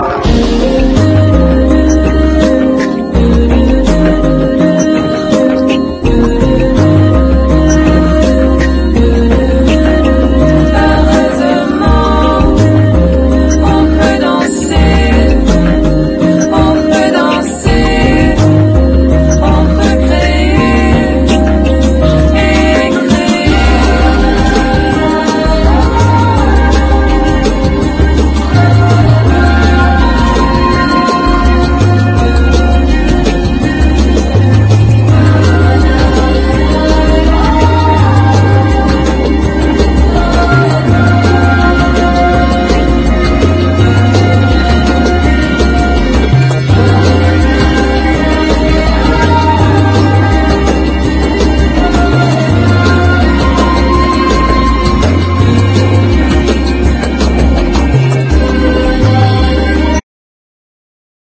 ボンゴがフィーチャーされた珍しい現代音楽！
ボンゴという打楽器が秘める無限の可能性と、切っても切ってもボンゴな瞬間が折り重なる大曲！